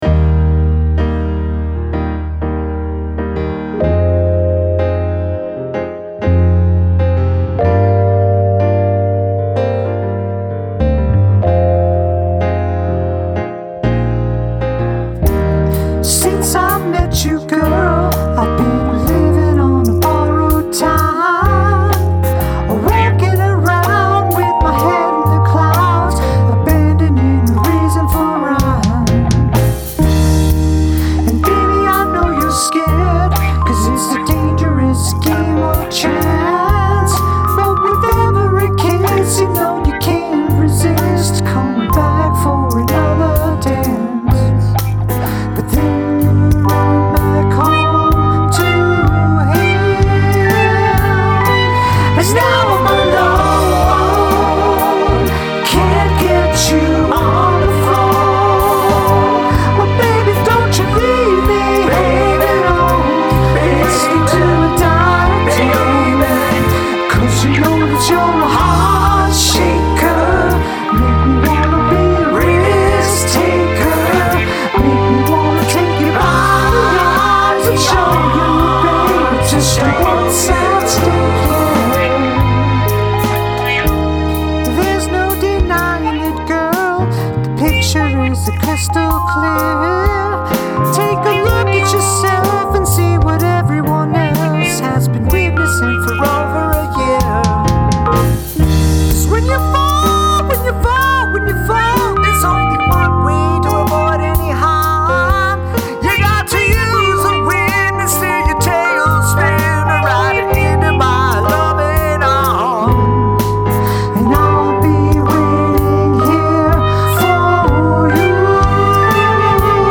Creative use of flatline tone.
Has shitloads of clipping on the vocals.